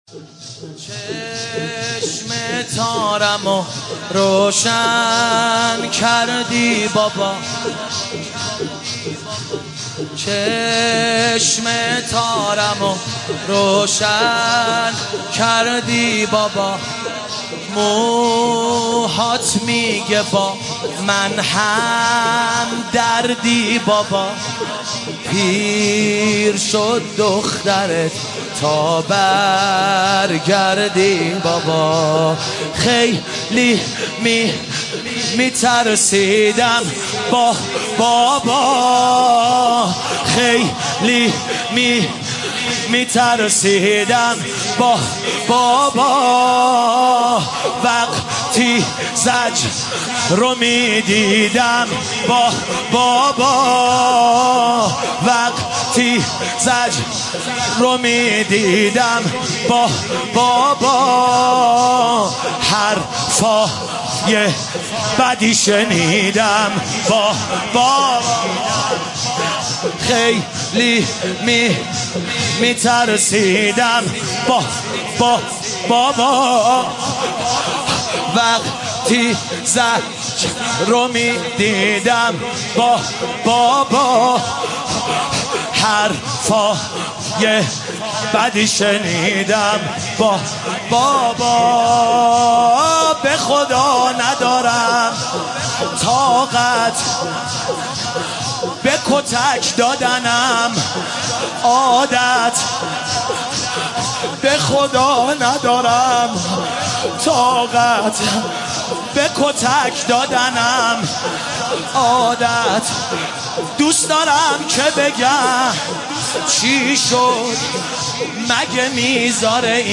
شب سوم محرم
نوحه جديد
مداحی صوتی